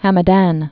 (hămə-dăn, -dän)